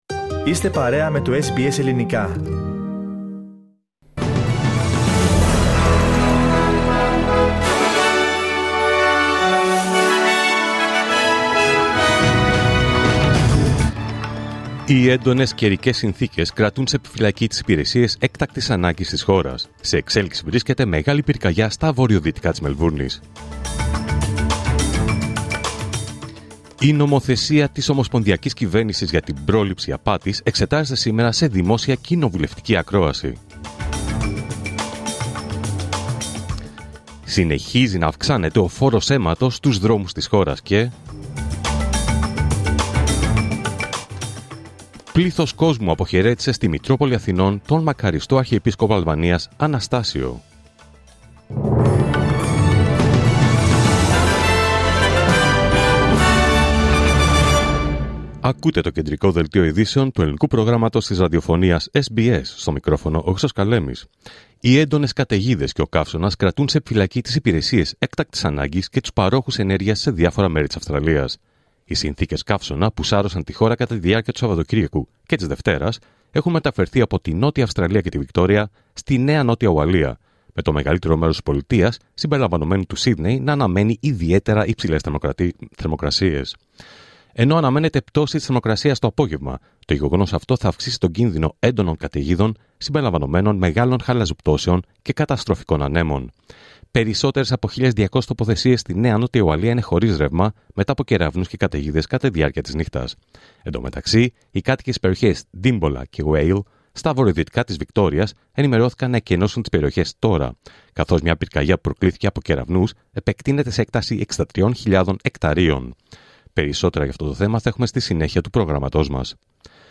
Δελτίο Ειδήσεων Τρίτη 28 Ιανουαρίου 2025